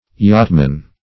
yachtman - definition of yachtman - synonyms, pronunciation, spelling from Free Dictionary
Yachtman \Yacht"man\ (y[o^]t"man), n.